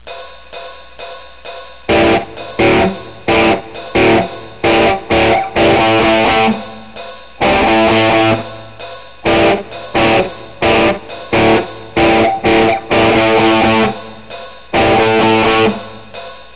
Обычно различия едва уловимы: один играет отдельные ноты, второй долбит по аккордам - но они определенно добавляют колориту.